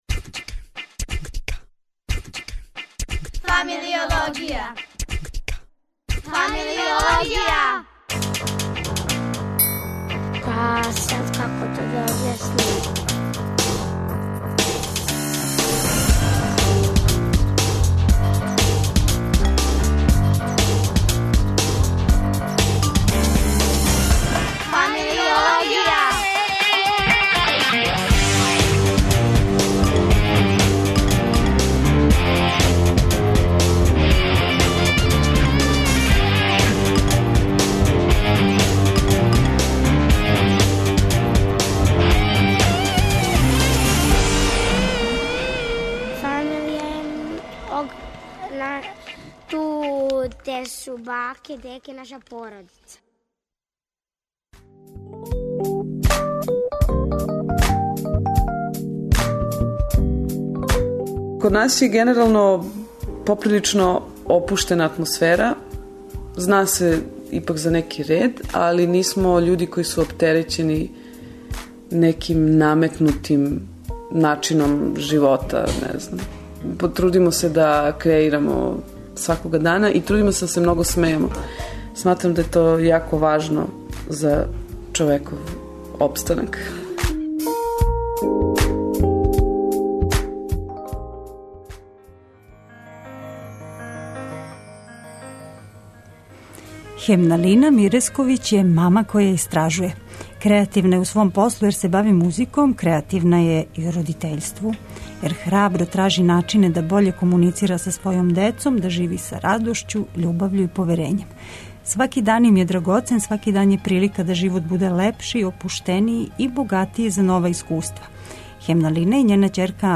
гошћом у студију